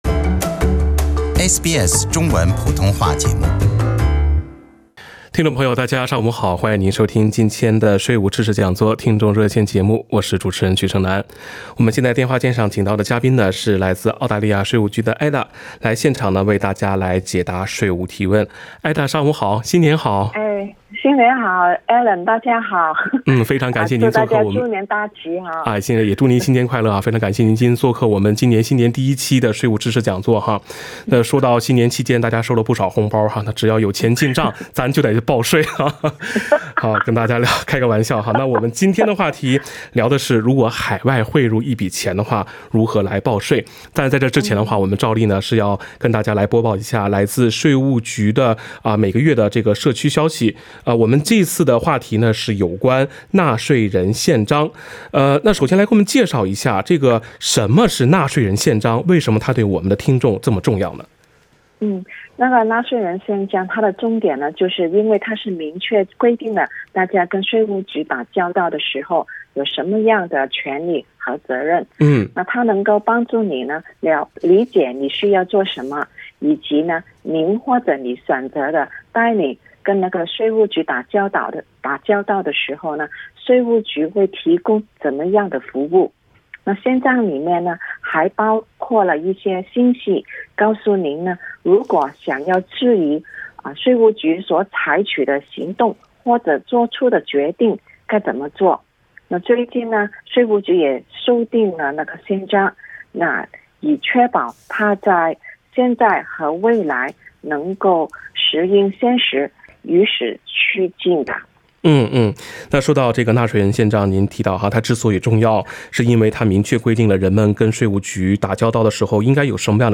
《税务知识讲座》听众热线逢每个月第二个周一上午8点30分至9点播出。